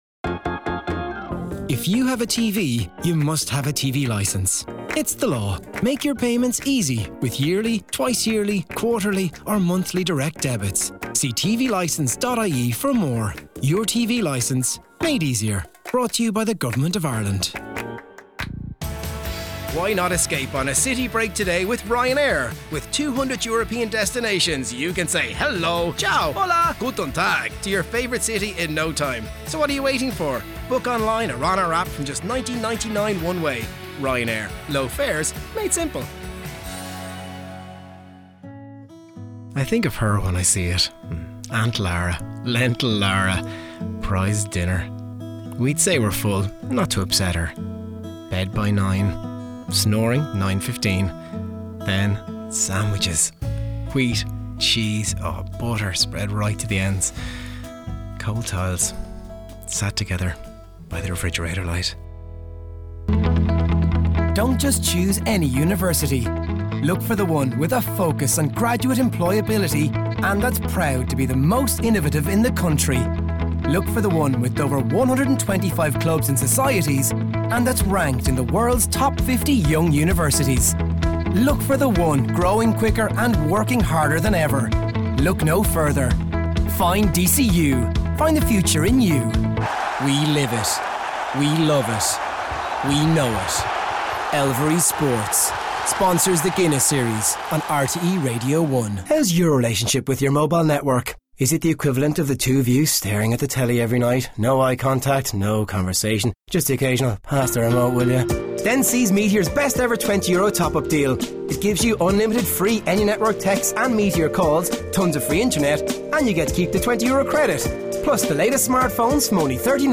Male
Home Studio Setup
Shure SM7B Mic, Aston Halo Reflection Filter.
30s/40s, 40s/50s
Irish Neutral